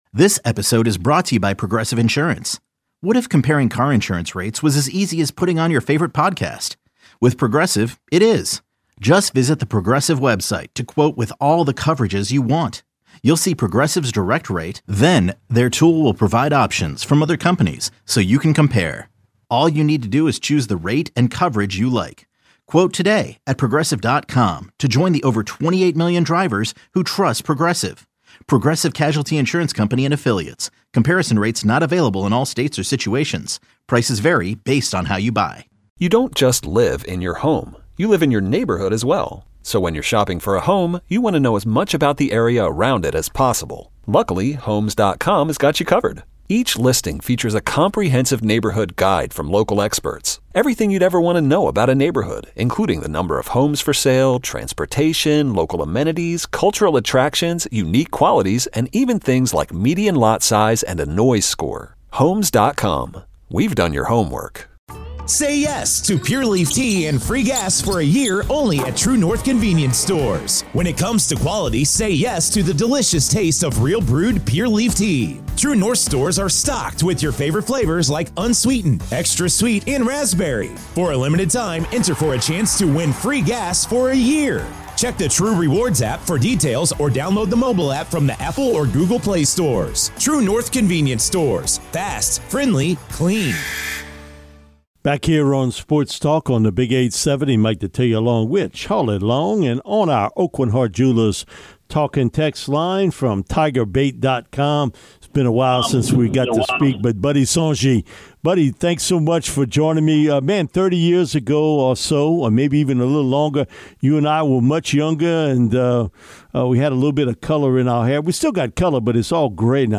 LSU interviews, press conferences and more